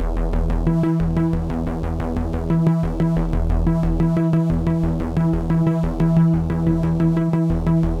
Index of /musicradar/dystopian-drone-samples/Droney Arps/90bpm
DD_DroneyArp3_90-E.wav